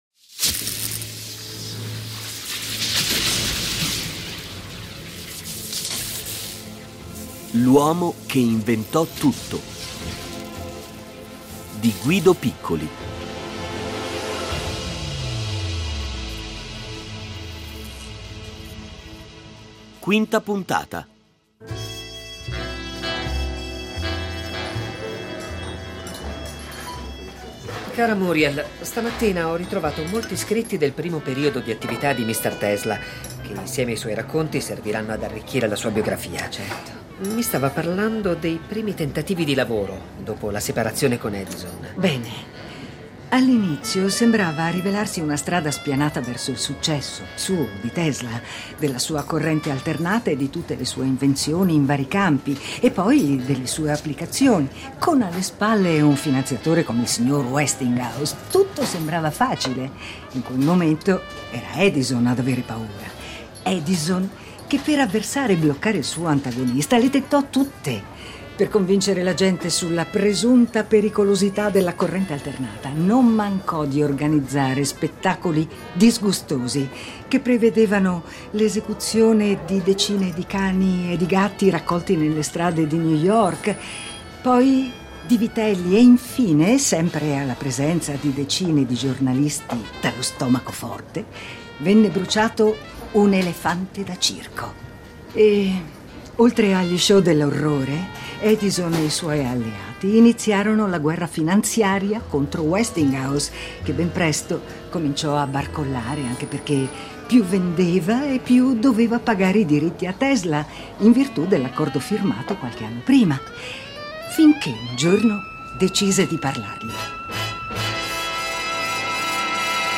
Nello sceneggiato compaiono familiari e vari amici dello scienziato, come Mark Twain, insieme con imprenditori, giornalisti, storici e altri tecnici e scienziati che lo frequentarono, a cominciare dal suo eterno grande nemico Thomas Edison.